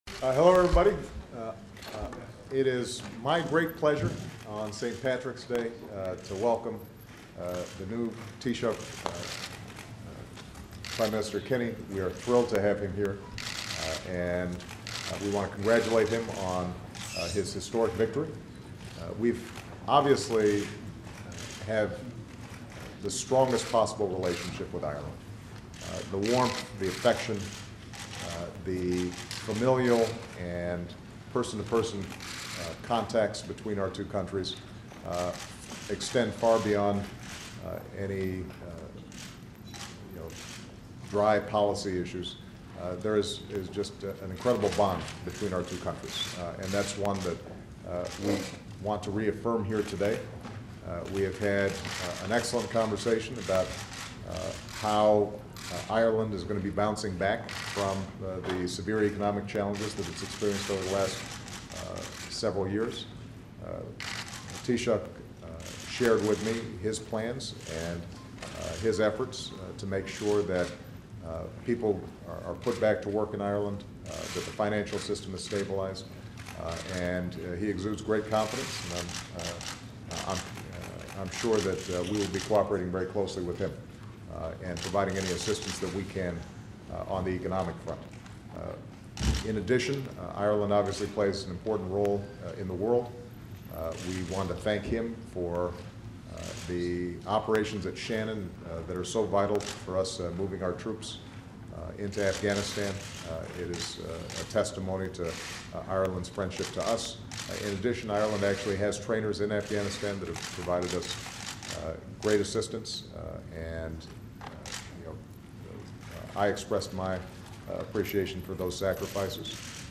U.S. President Barack Obama and Irish Prime Minister Enda Kenny speak to the press in the Oval Office
Recorded at the White House Oval Office, Mar. 17, 2011.